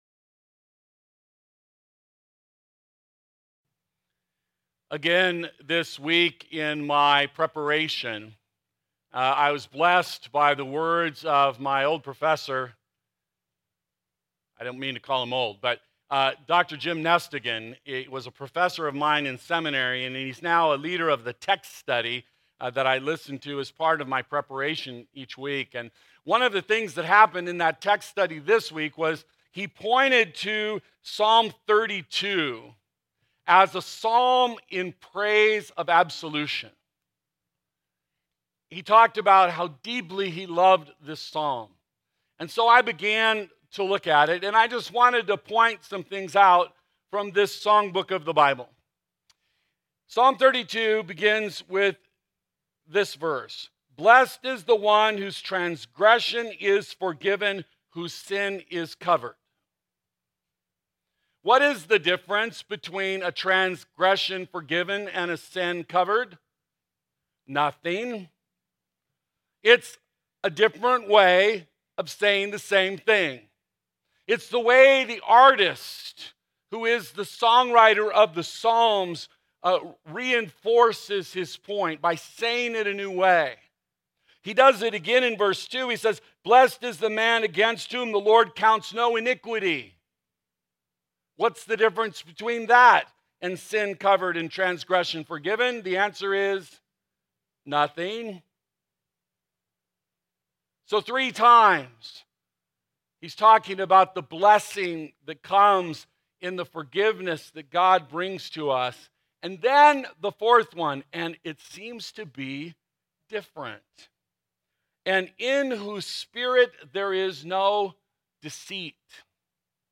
Sermons – Desert Hope Lutheran Church